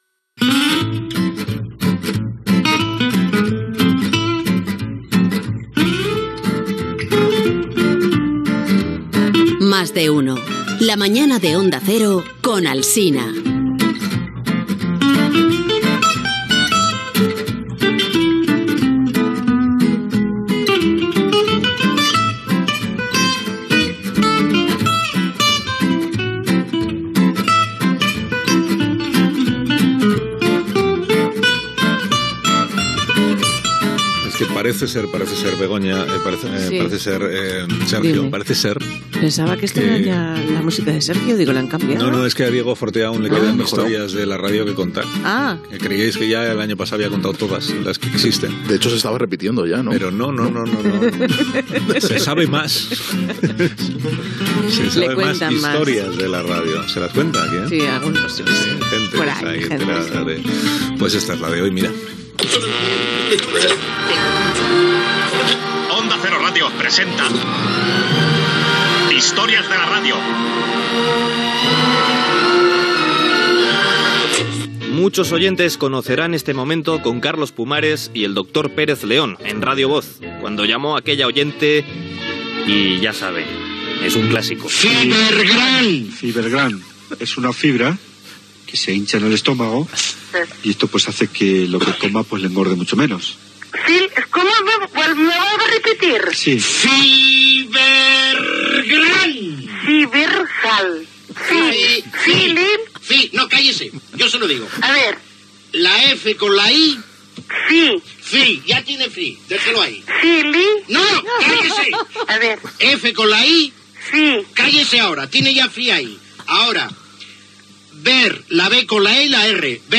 Indicatiu del programa, secció "Historias de la radio": Carlos Pumares i una oïdora que no sap com escriure la marca Fibergrán i Encarna Sánchez que intenta apuntar el nom d'un hospital francès que li dona un oïdor, a Radio Miramar, l'any 1979
Info-entreteniment